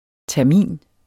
Udtale [ tæɐ̯ˈmiˀn ]